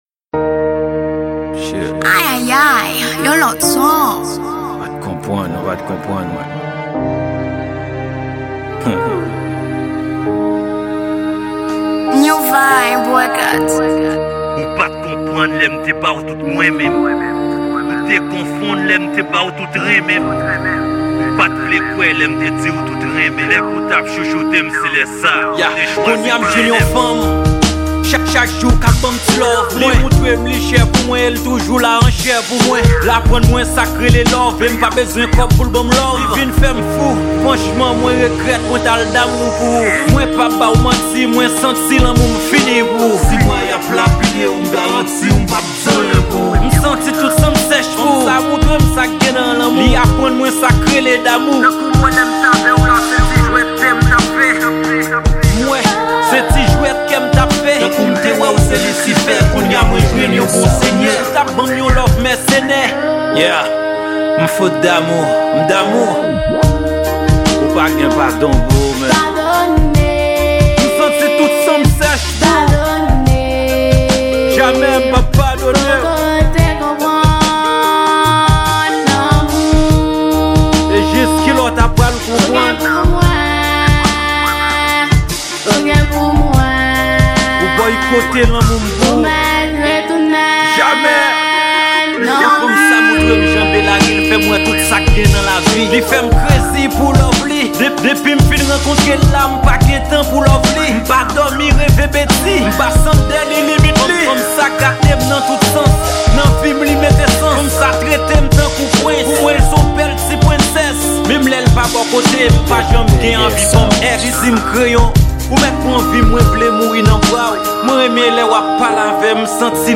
Genre: R&B. ./